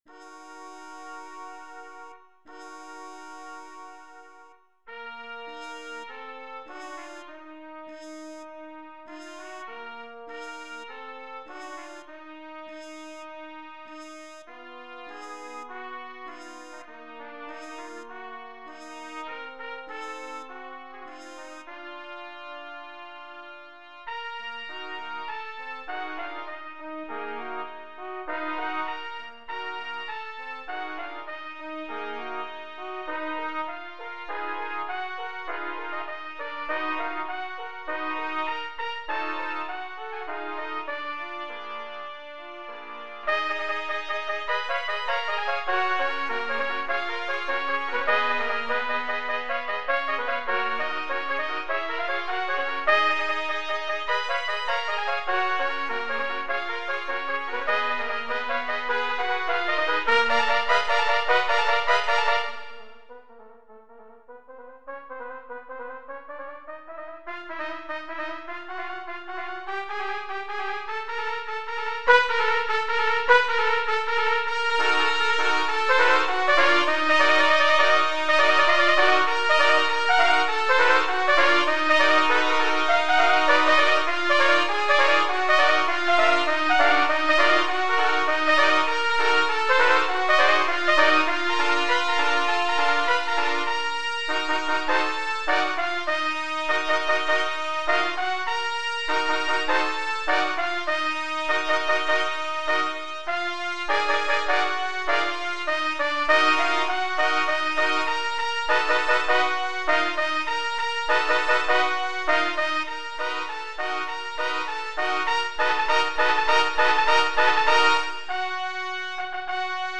is a riveting tone poem
scored for eight trumpets